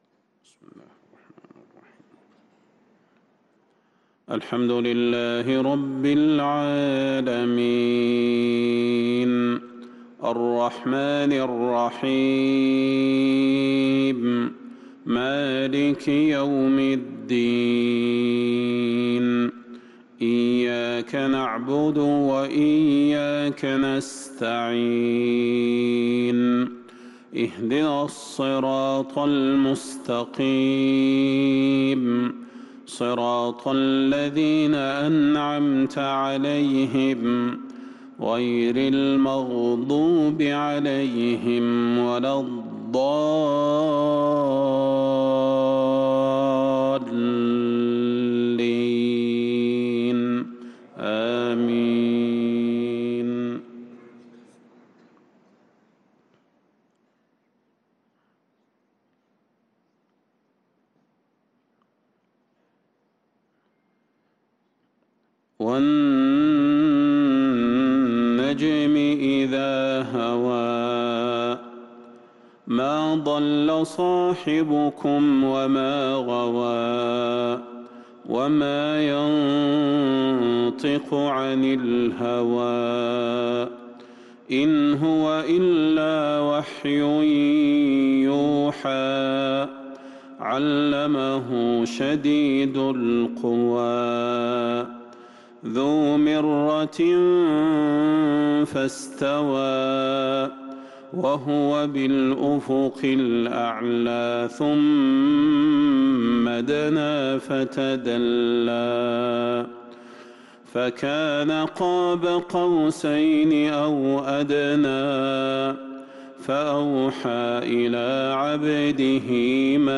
صلاة الفجر للقارئ صلاح البدير 22 شوال 1443 هـ